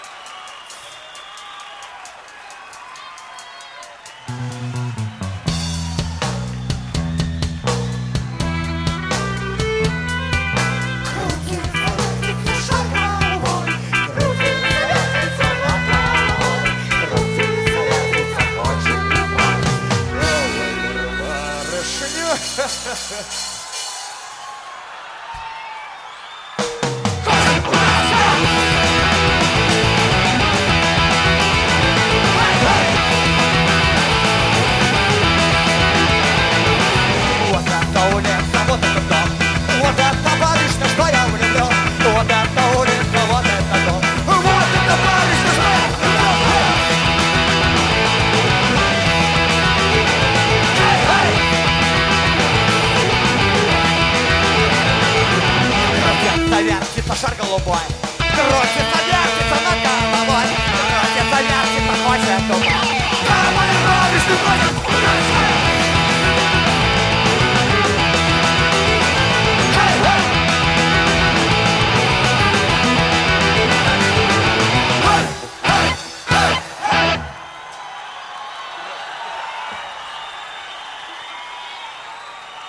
Метал
Жанр: Метал / Рок